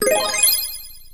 match-start.wav